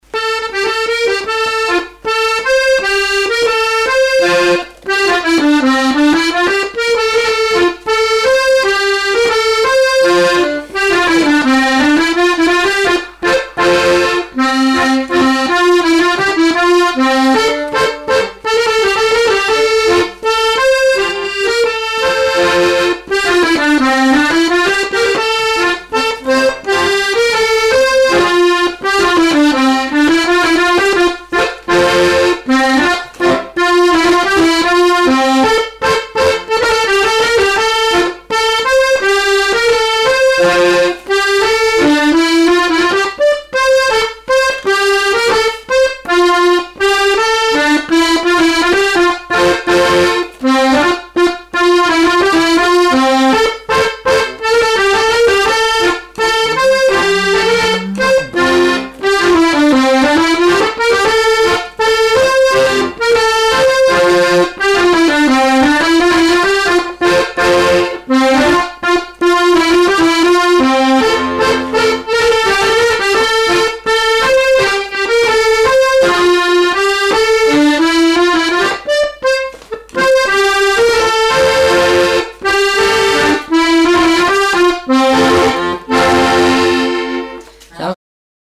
danse : scottish
Répertoire du musicien sur accordéon chromatique
Pièce musicale inédite